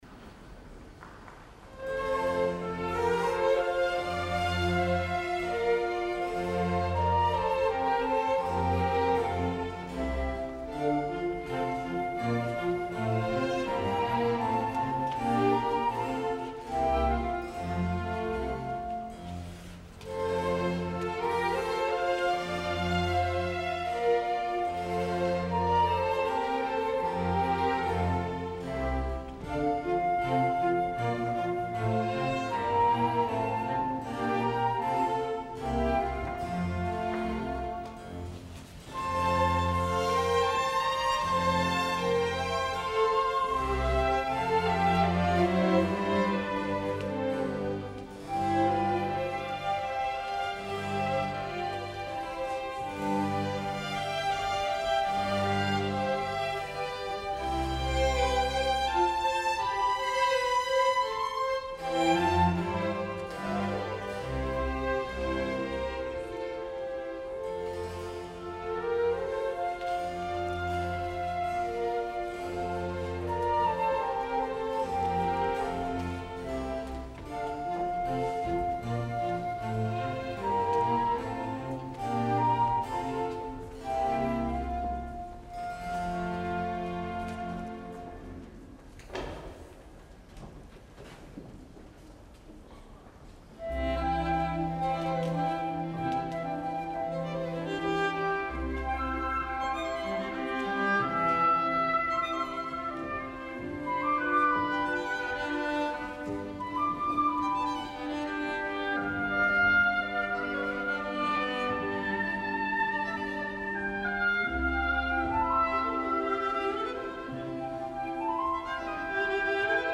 Sona malament aquest Orfeo?, no, en absolut hi ha sonoritats molt interessants, de recerca i aproximació a un so original ben peculiar, el tempo és pausat, potser massa i sincerament no crec que en aquesta interpretació és pugui apreciar la gran reforma que Gluck va proposar, més aviat la sonoritat és massa gruixuda o fins i tot encotillada, els cors són massa catedralicis mentre que els magnífics solistes, aquests sí, donen el millor, especialment Mehta, en una representació/interpretació que m’ha donat la sensació d’excessivament distant.
Per les fotografies tot sembla indicar que aquesta vegada també ha valgut més la pena escoltar la transmissió de ràdio que el vídeo, per tant concentrem-nos en la part vocal i musical.
Us deixo el deliciós “Che puro ciel” per Mehta i un acompanyament peculiar i a estones sorprenent, ja em direu que us sembla
Orfeo – Bejun Mehta
Staatskapelle Berlin
Director musical: Daniel Barenboim
Staatsoper Berlin im Schillertheater, Berlin